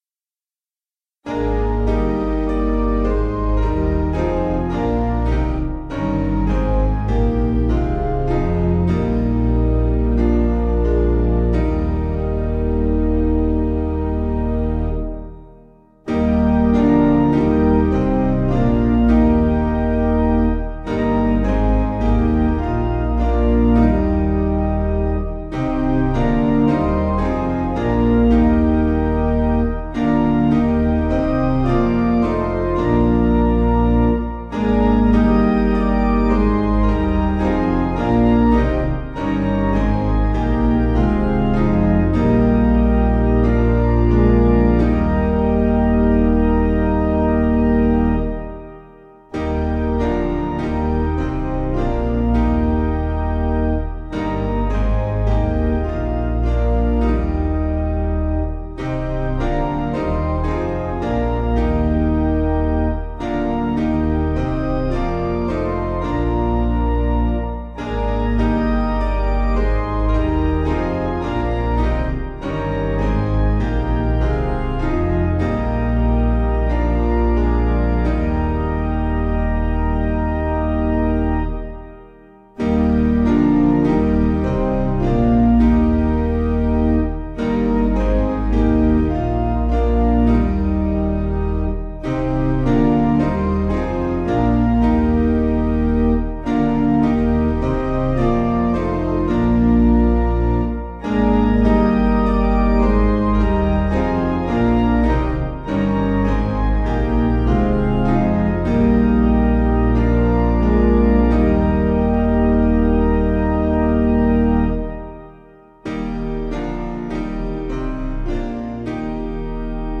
Key: E♭ Major